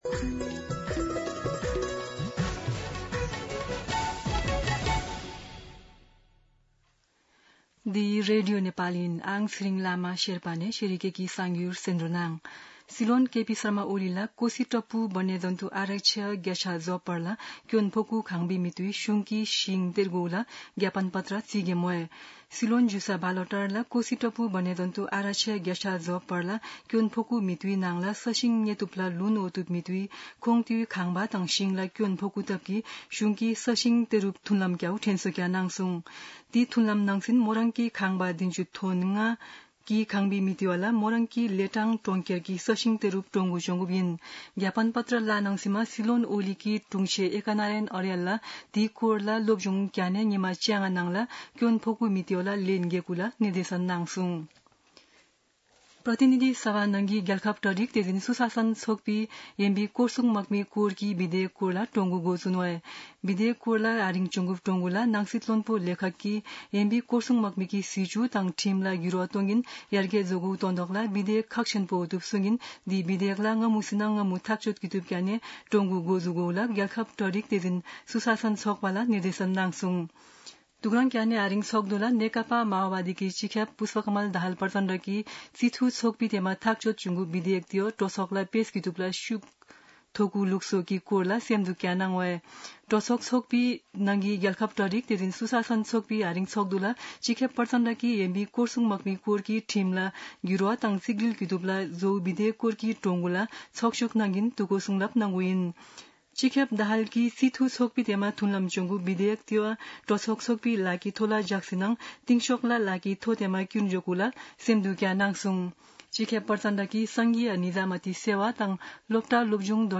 शेर्पा भाषाको समाचार : ११ असार , २०८२
Sherpa-News-3-11.mp3